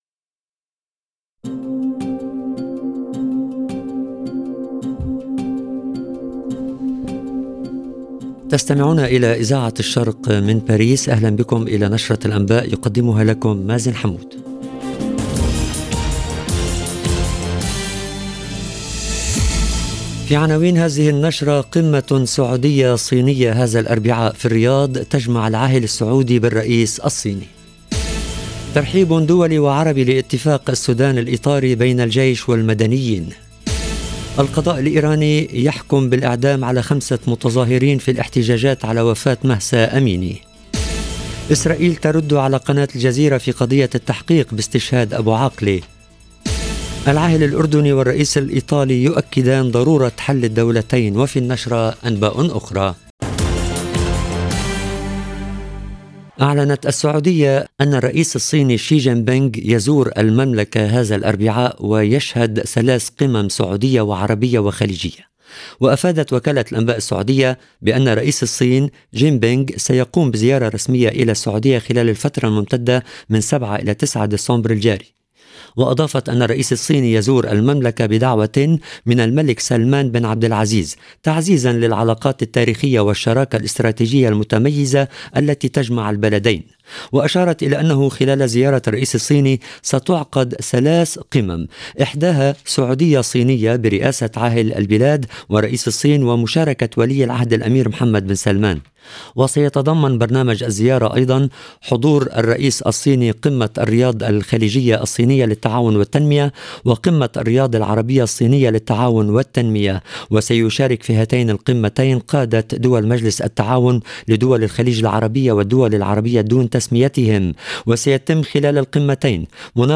LE JOURNAL EN LANGUE ARABE DU SOIR DU 6/12/22